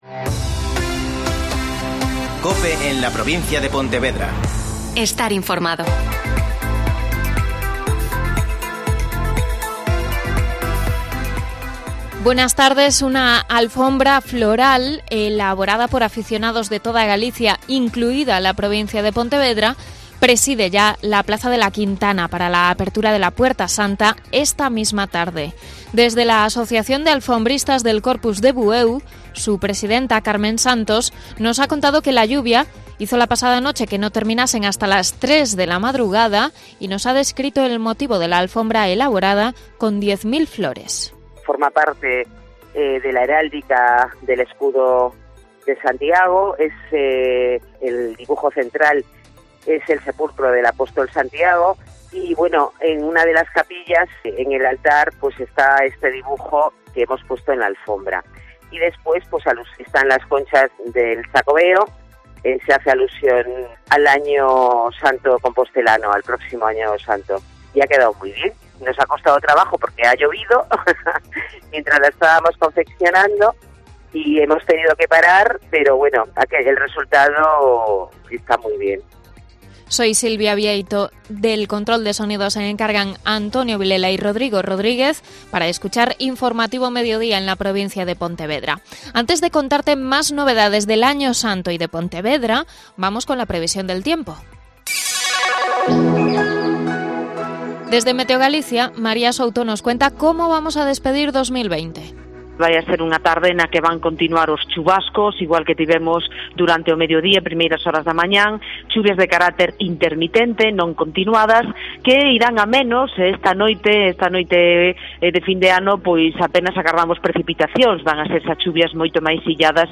Mediodía COPE Pontevedra (Informativo 14:20h)